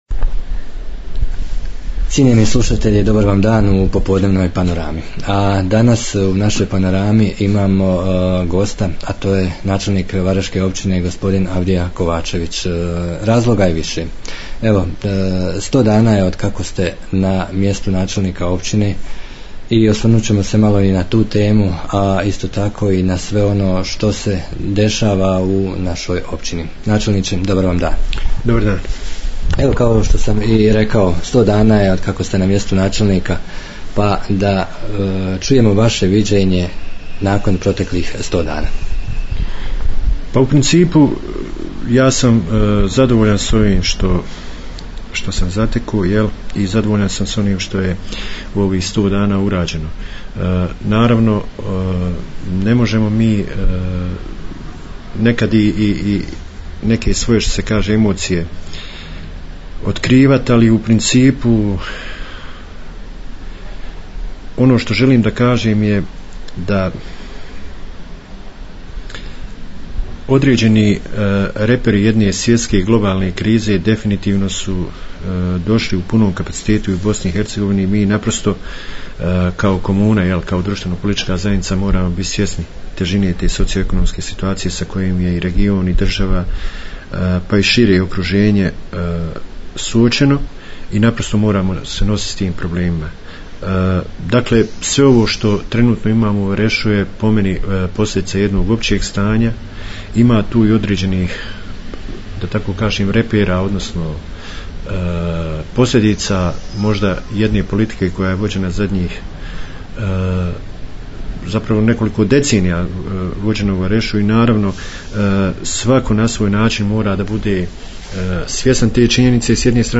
Prošlo je 100 dana od kako je promijenjena struktura vlasti u Varešu. Kao što smo i obećali na kraju mjeseca razgovaramo sa načelnikom općine Avdijom Kovačevićem, tako je to i ovaj puta.